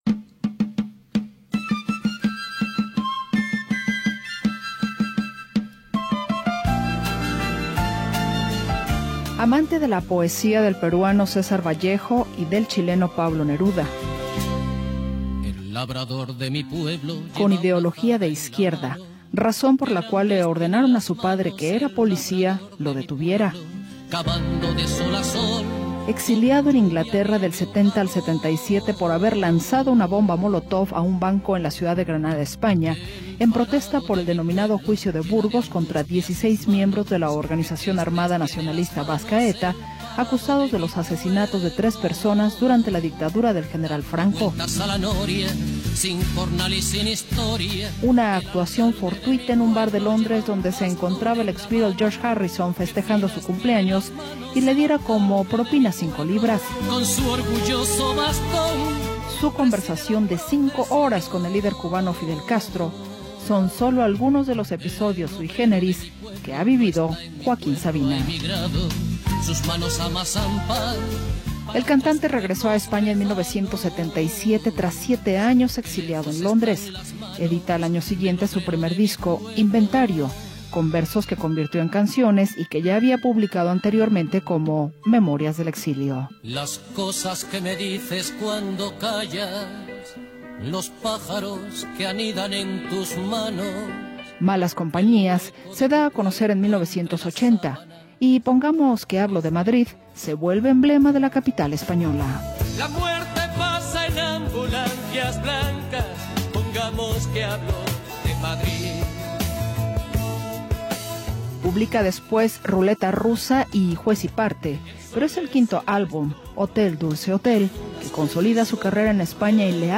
Reconocido por su voz rasposa y su estilo poético, combina rock, folk y canción de autor con letras cargadas de ironía, romanticismo y mirada urbana.